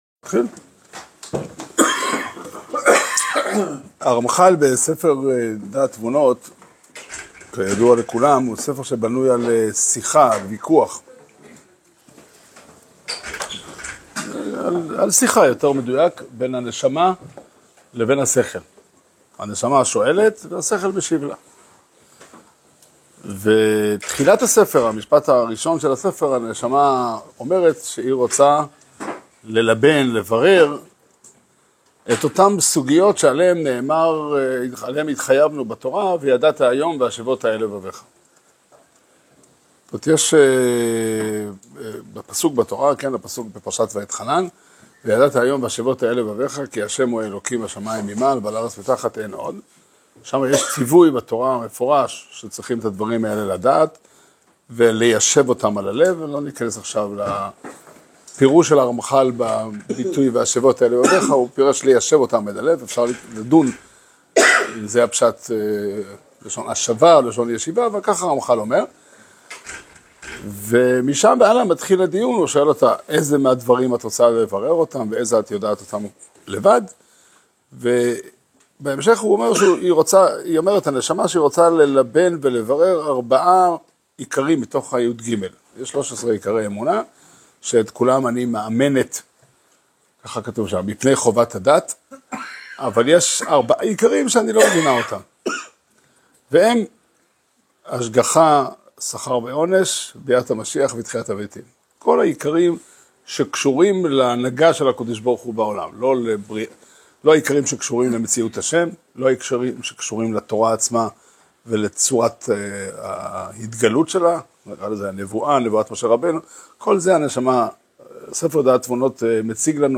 שיעור שנמסר בבית המדרש 'פתחי עולם' בתאריך כ' חשוון תשפ"ה